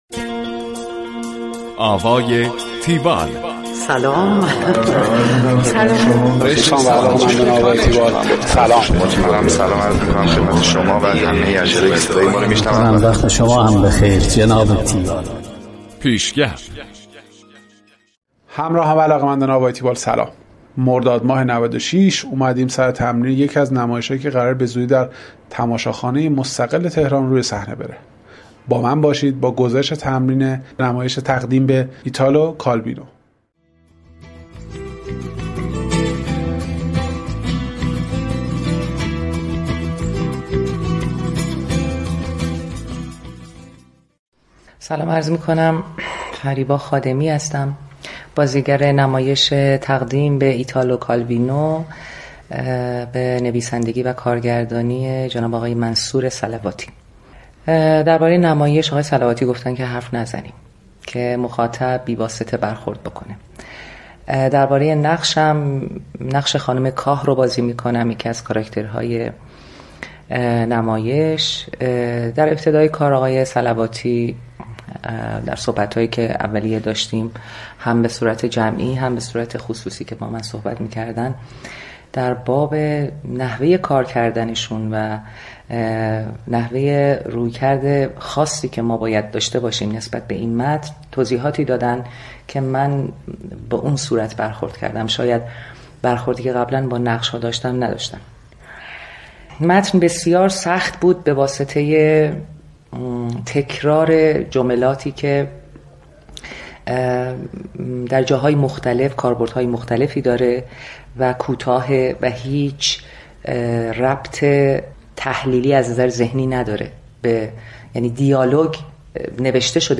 گزارش آوای تیوال از نمایش تقدیم به ایتالو کالوینو